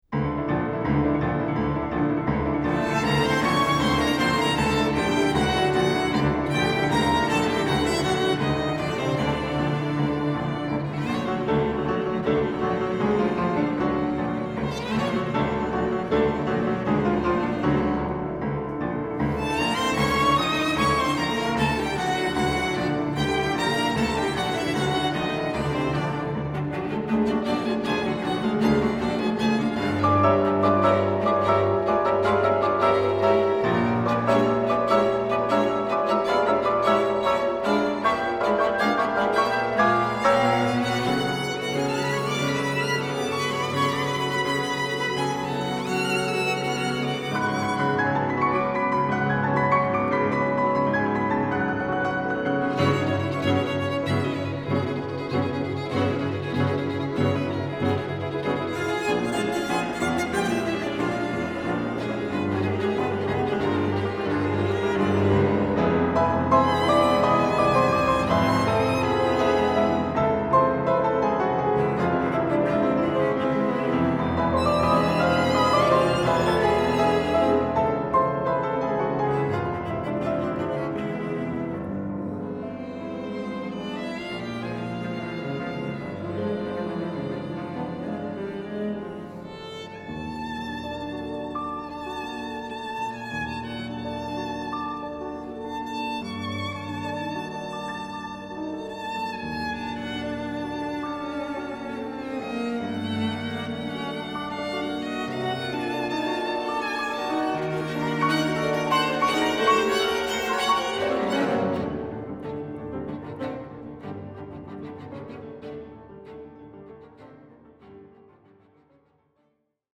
piano 2:00 2.